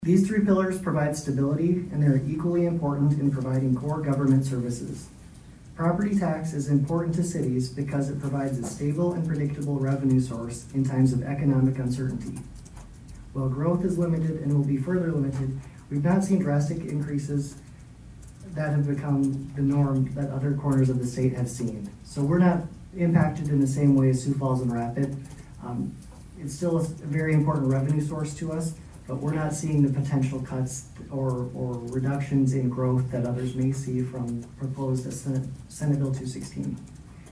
ABERDEEN, S.D.(HubCityRadio)- On Monday night, the Aberdeen City Council did a work session to address the budget for the year 2026.